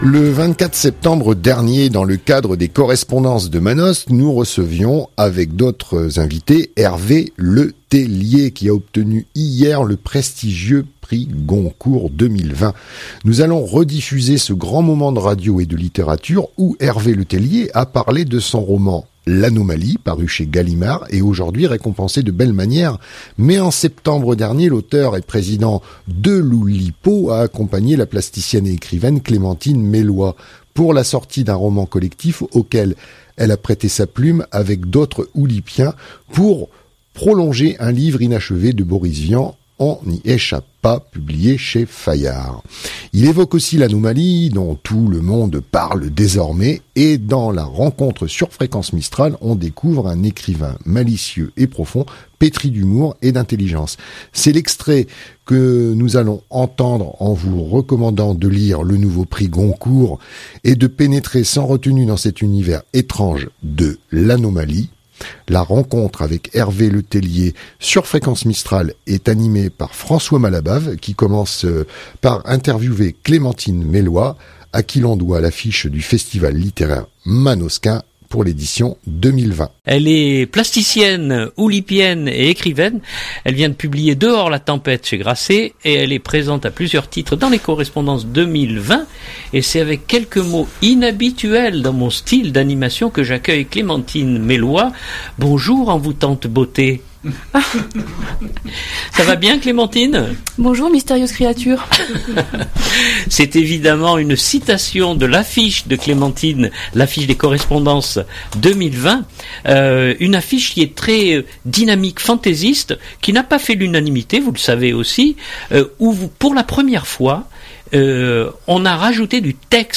Prix Goncourt Hervé Le Tellier, prix Goncourt sur Fréquence Mistral (13.55 Mo) Le 24 septembre dernier dans le cadre des Correspondances de Manosque, nous recevions avec d’autres invités Hervé Letellier qui a obtenu hier le prestigieux prix Goncourt 2020.